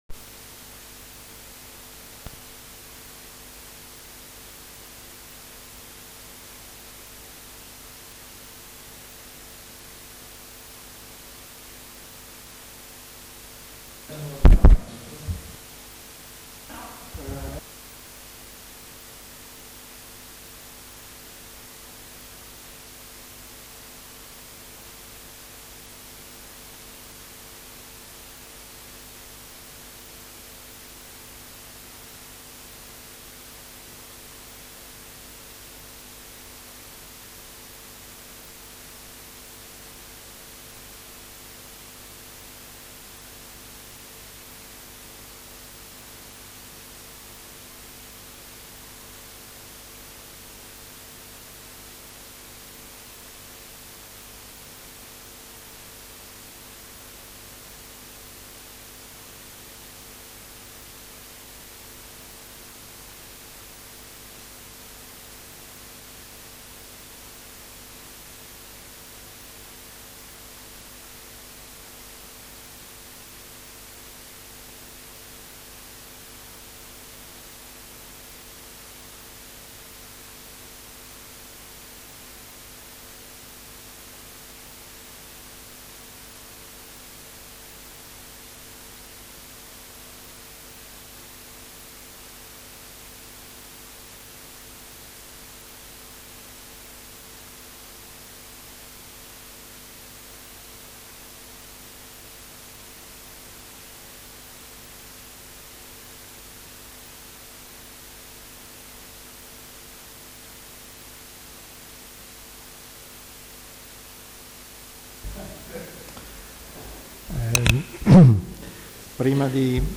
Audio registrazioni delle sedute dei Consigli Comunali per la legislatura 2017/2022